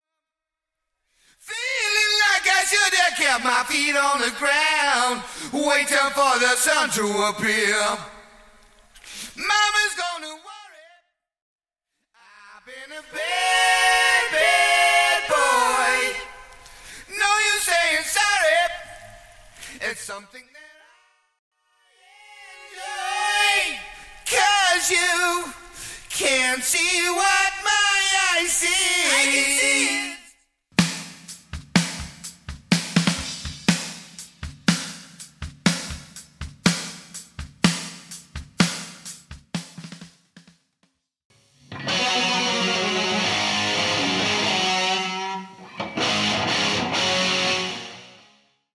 Studio Backing Strings Stem
Studio Bassline Stem
Studio Cymbals Stem
Studio Guitars Stem
Studio Kick Stem
Studio Snares Stem